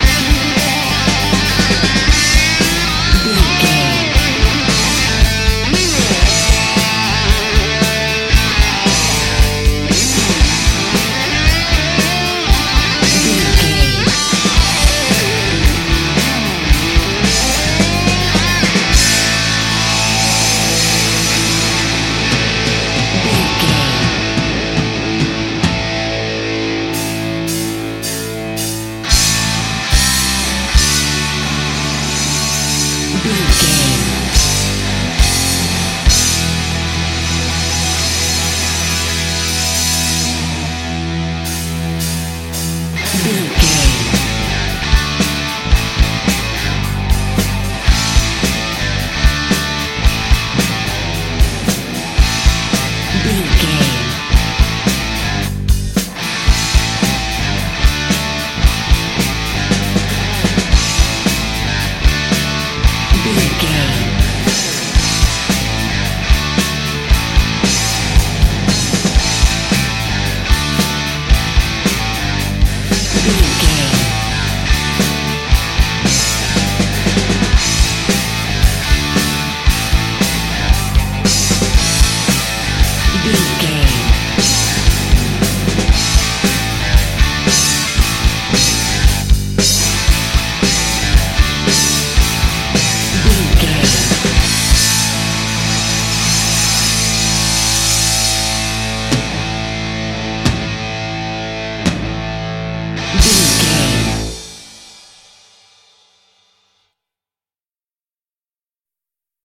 Ionian/Major
drums
electric guitar
lead guitar
bass
aggressive
energetic
intense
powerful
nu metal
alternative metal